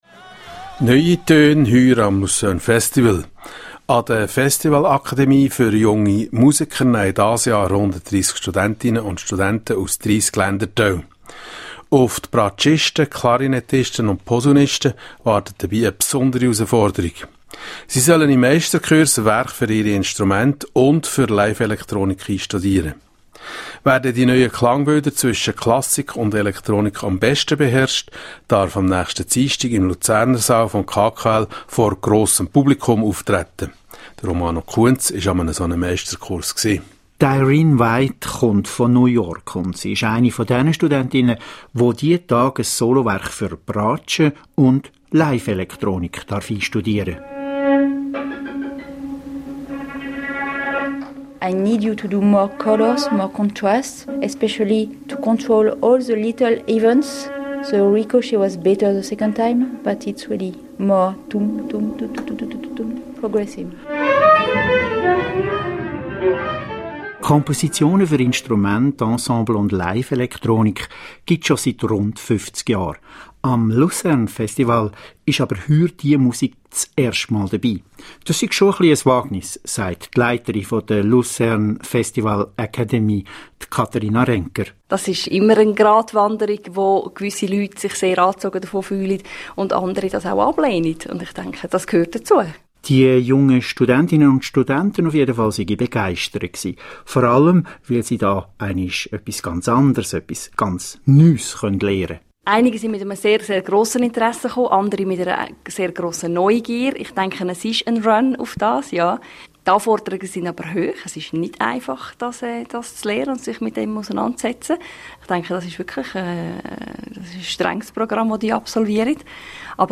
A short radio piece on the festival and the masterclasses.